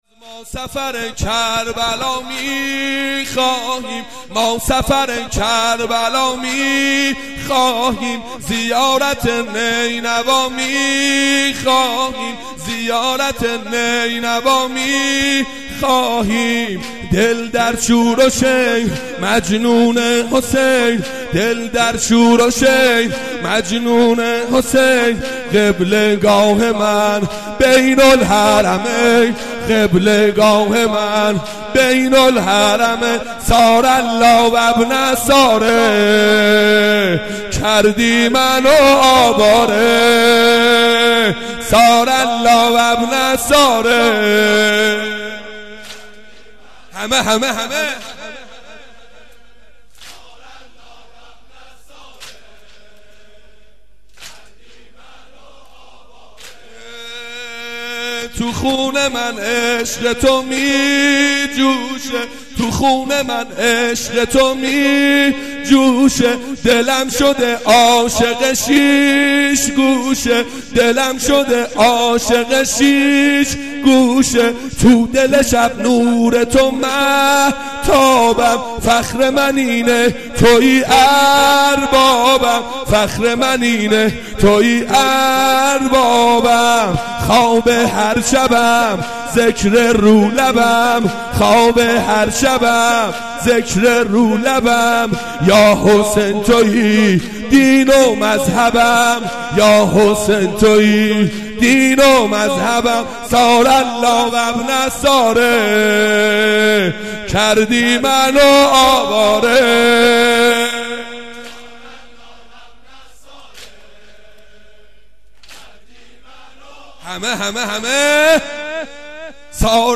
گلچین سینه زنی
شور